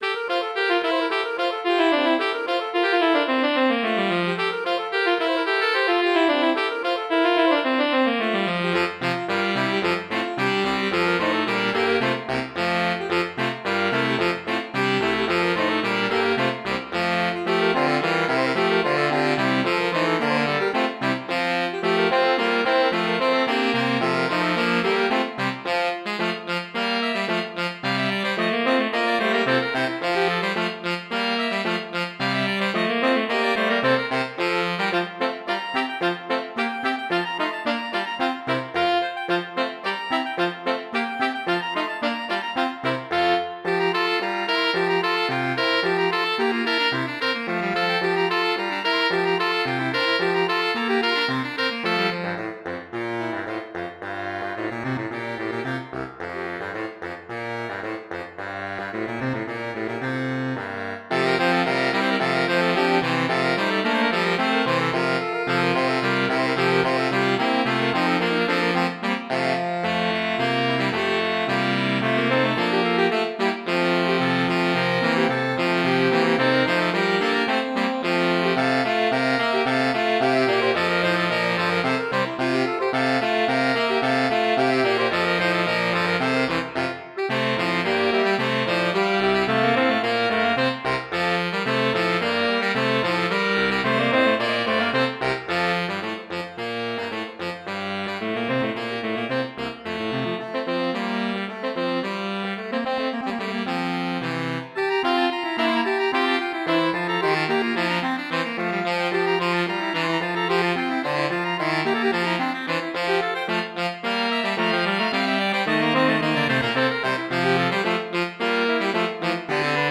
4 SATB. A tune for English Morris dancing. Very fast.